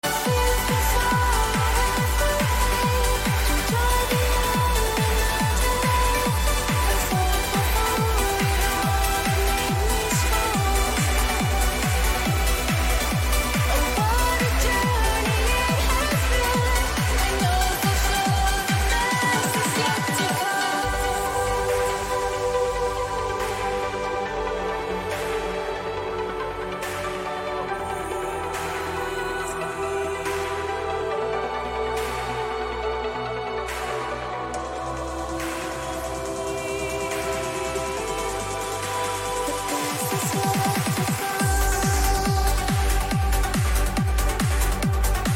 uplifting trance